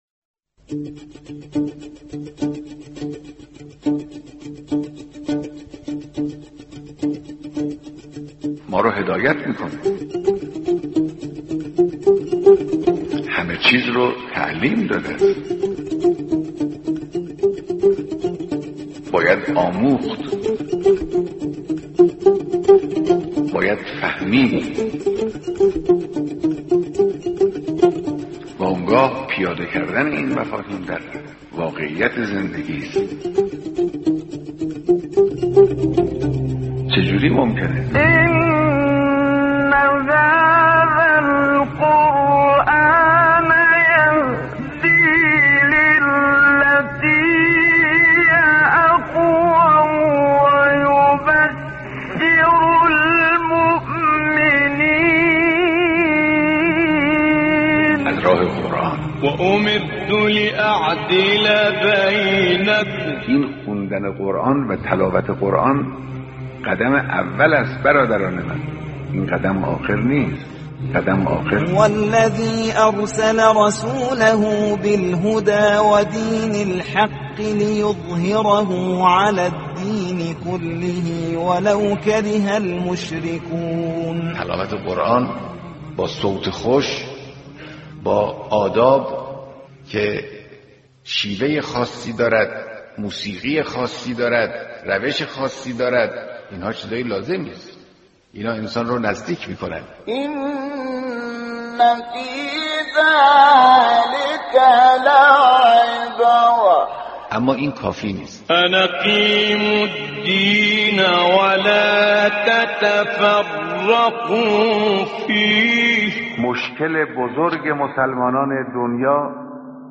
کلیپ صوتی از بیانات رهبر انقلاب